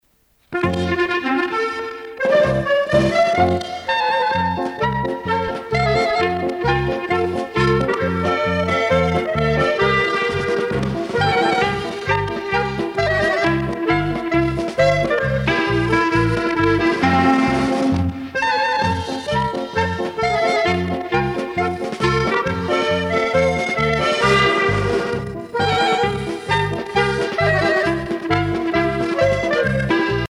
Orchestre de variétés
Pièce musicale éditée